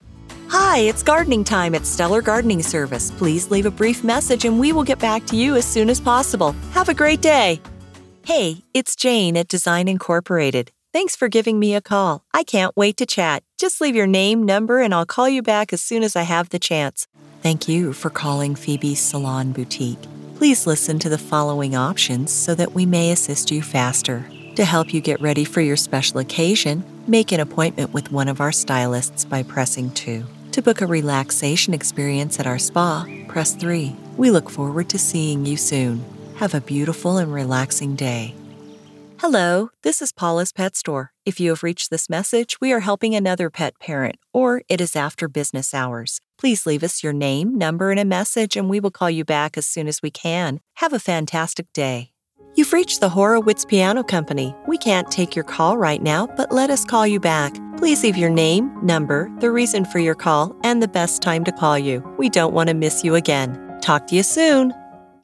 IVR/Voicemail Demo